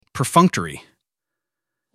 /pərfʌ́ŋktəri/【形】①（行動・態度が）おざなりの、いい加減な、形式的な ②やる気のない、熱意のない、うわべだけの